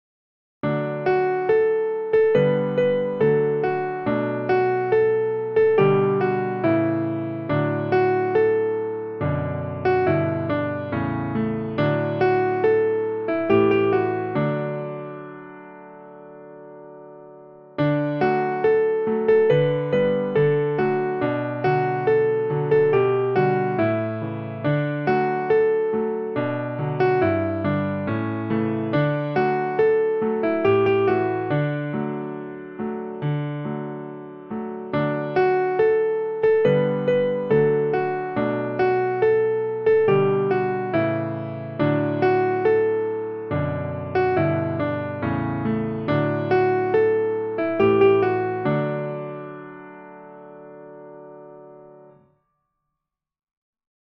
English Folk Song
has two verses with slightly different arrangements